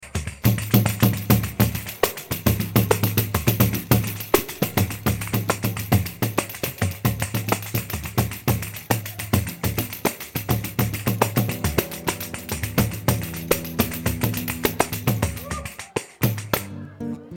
numa sinfonia de pandeiros